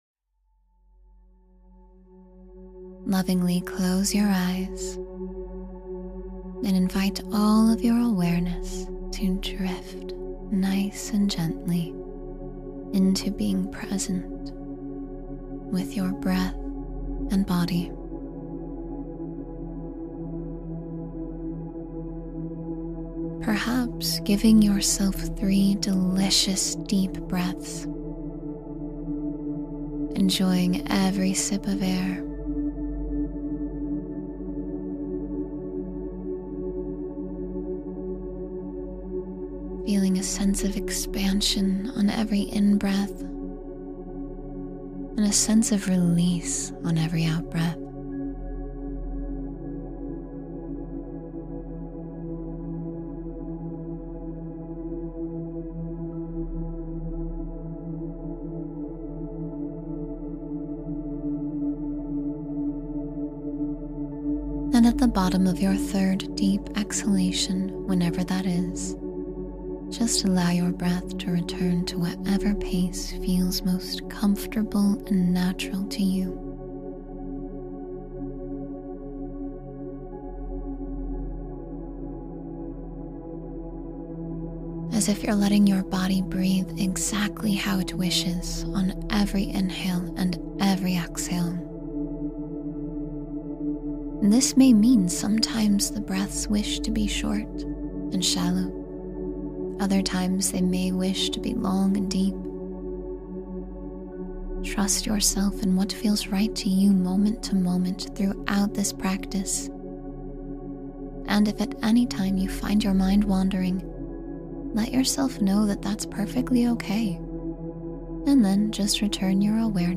Begin the Day Grateful and Grounded — Meditation for Gratitude and Inner Peace